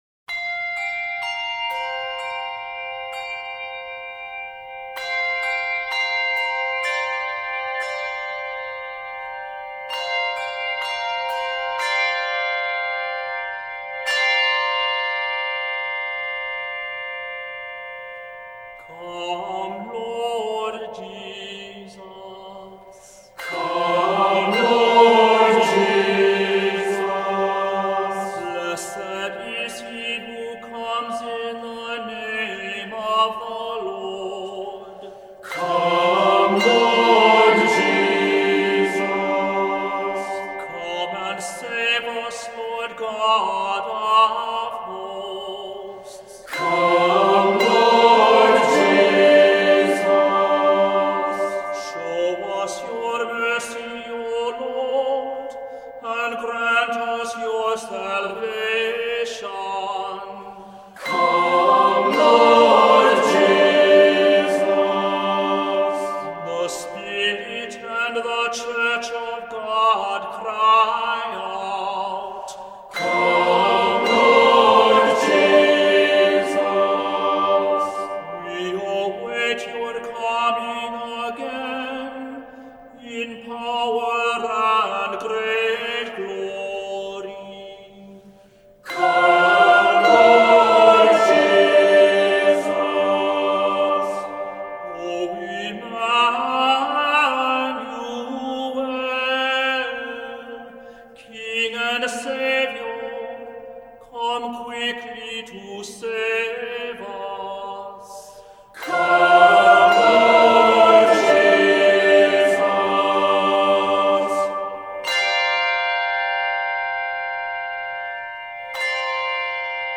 Voicing: Assembly,Cantor